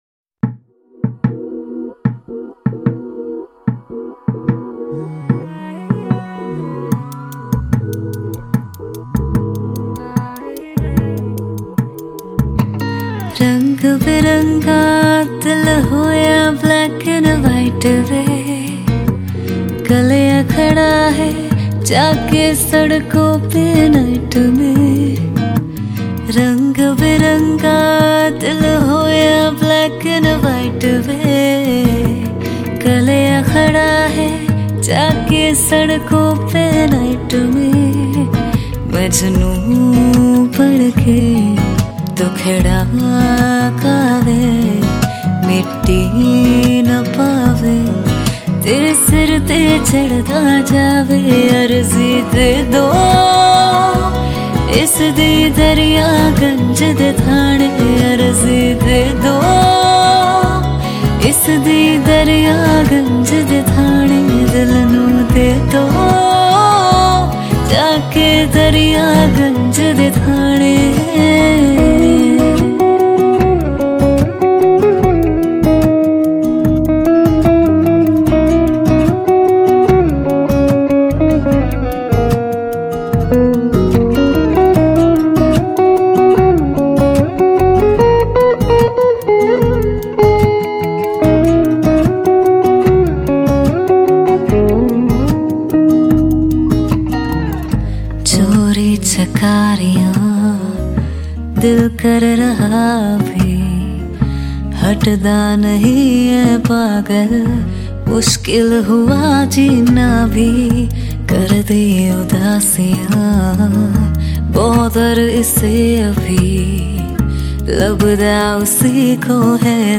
Bollywood Mp3 Music